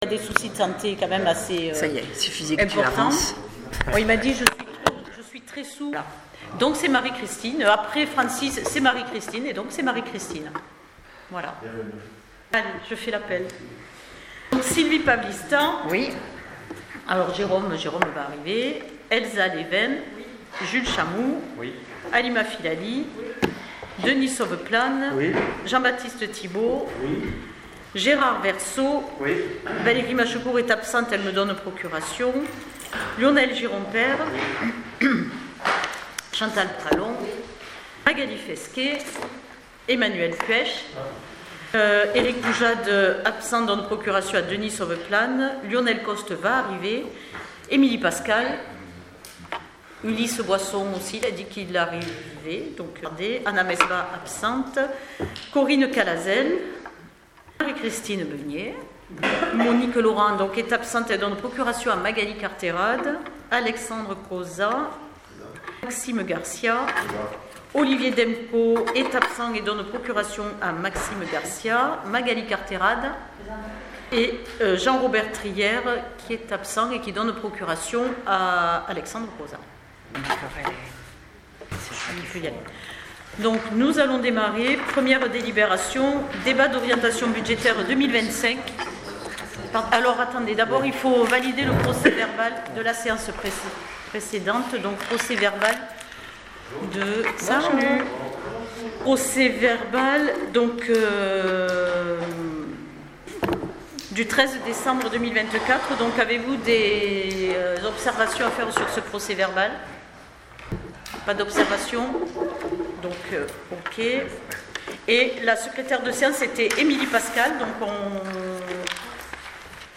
Conseil municipal du 7 février 25
Conseil-municipal-du-7-fevrier-25.mp3